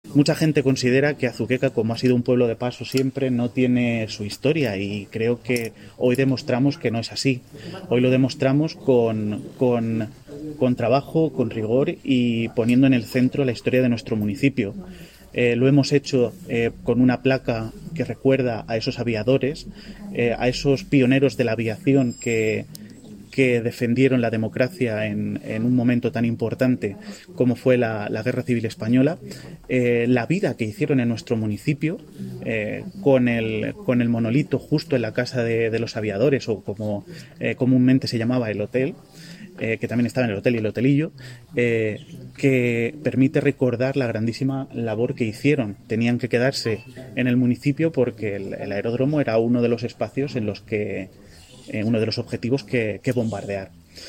Declaraciones del alcalde, Miguel Óscar Aparicio